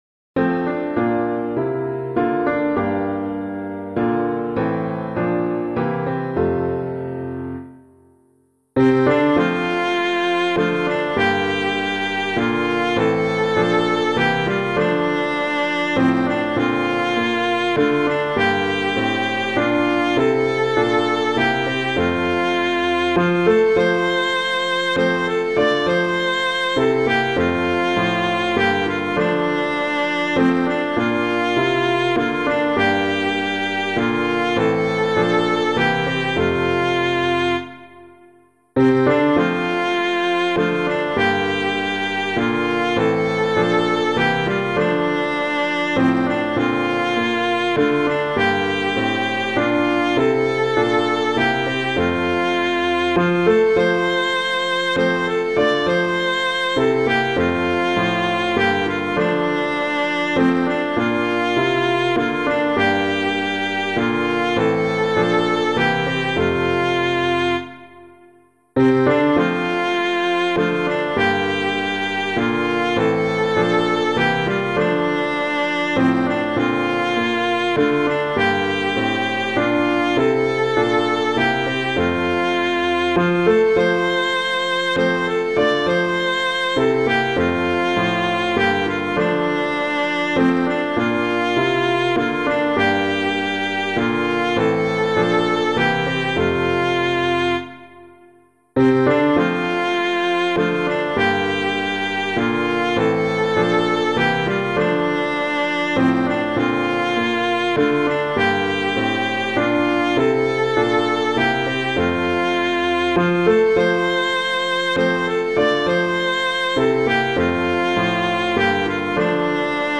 Hymn suitable for Catholic liturgy.